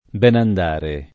benandare [ b H nand # re ] s. m.